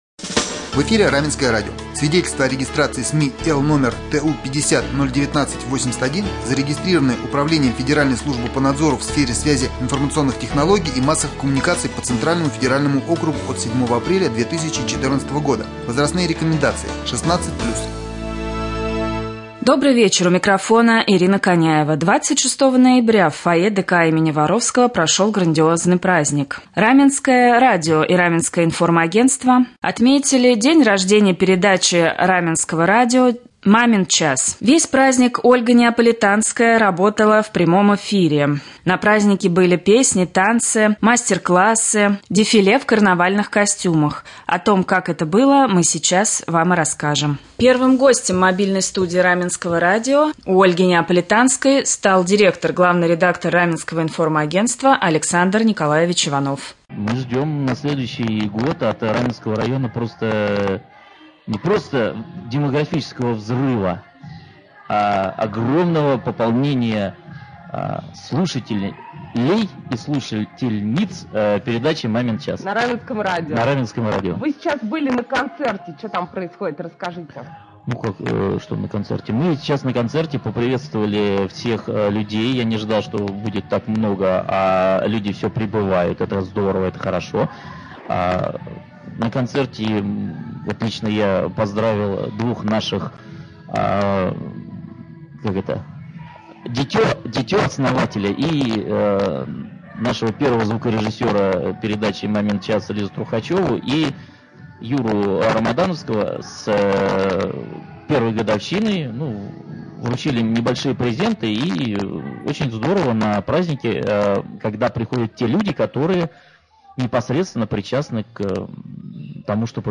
28 ноября 2016 года на Раменском радио впервые вышла передача «Мамин час». 26 ноября в ДК им.Воровского прошел праздник в честь первого дня рождения передачи.